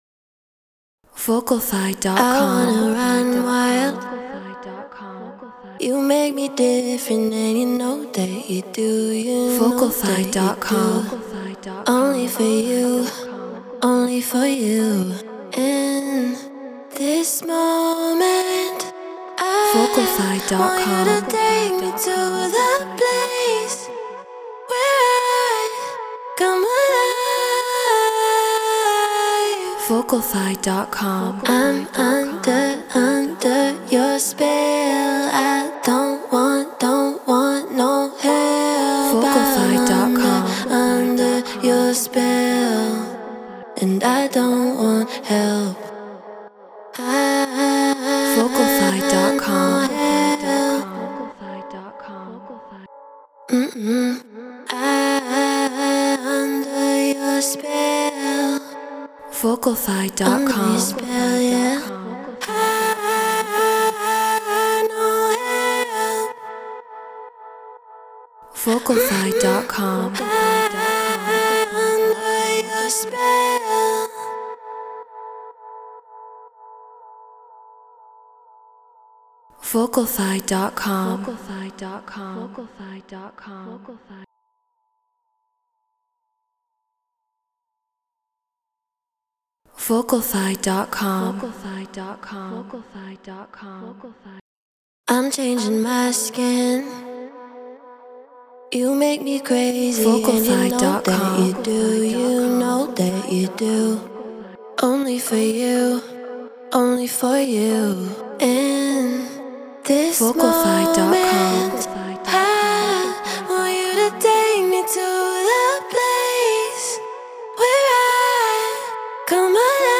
Deep House 124 BPM F#min
Treated Room